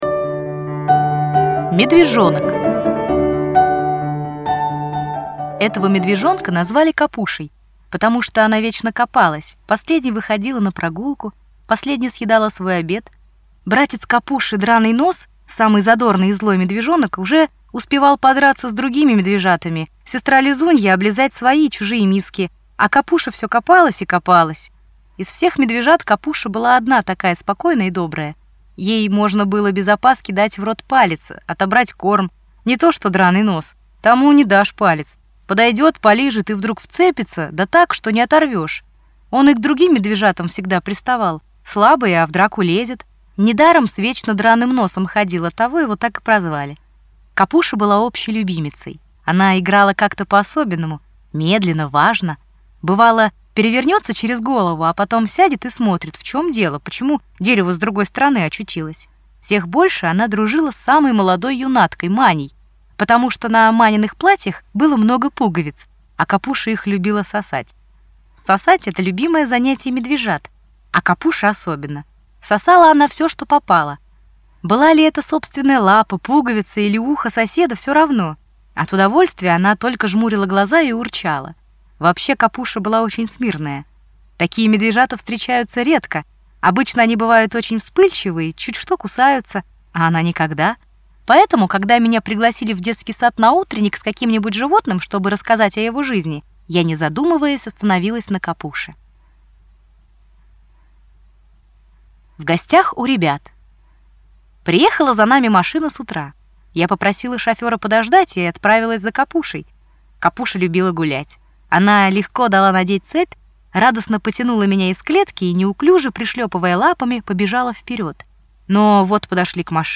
Аудиорассказ «Медвежонок»